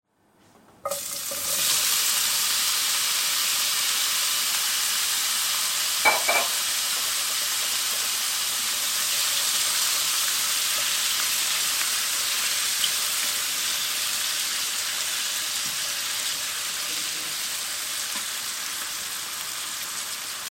谚语样本 " 我爱你
Tag: 讲话 谈话 声音 女孩 言语 声音 文字 爱情 女人